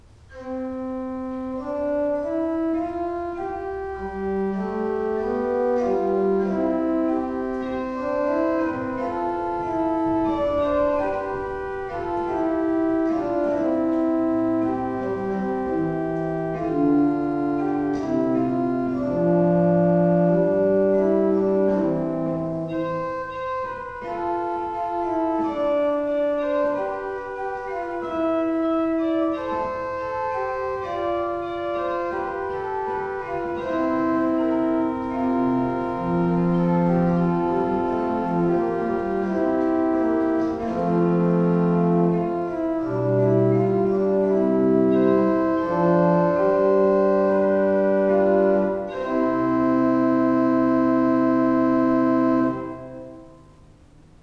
The Principal dulcis is a narrow-scaled Principal with the lowest octave of stopped metal.
Listen to an improvisation on the Principal dulcis 8' by clicking
Principal_Dulcis_8.wav